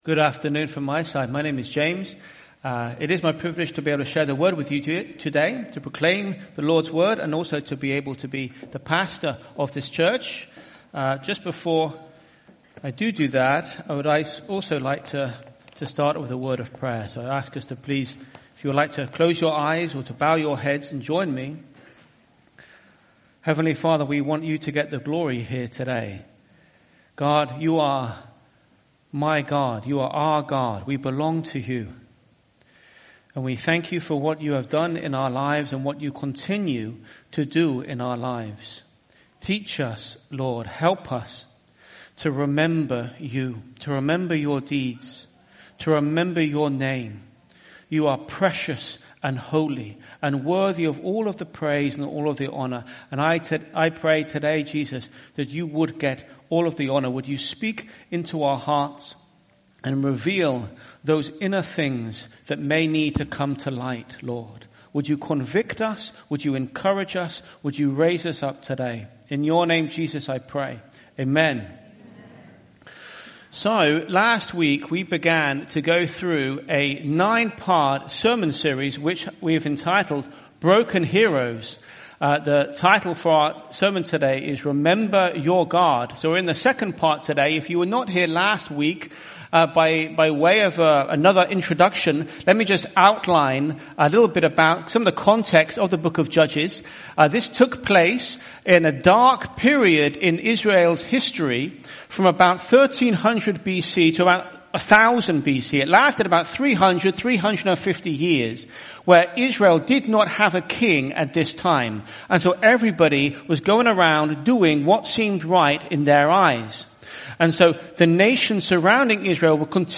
IBC Hamburg Sermon